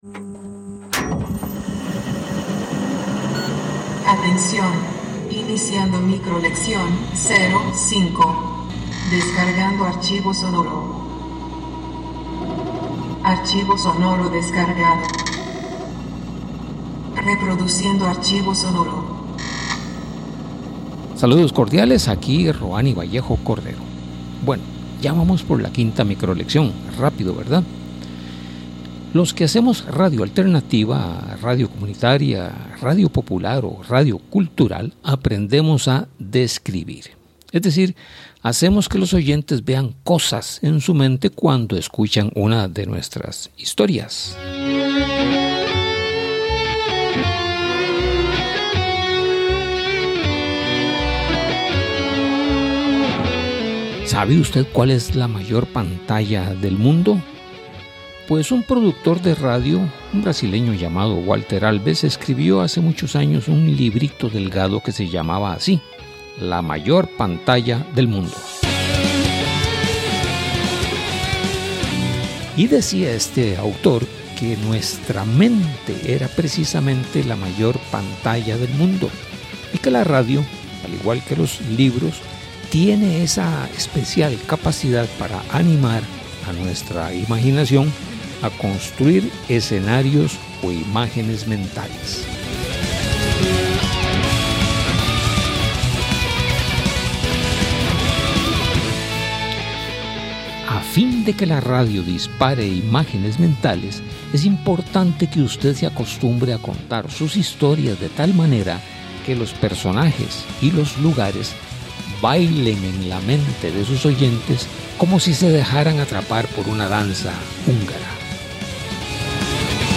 Esta es la micro-lección 05 del curso titulado: El ADN de la radio alternativa.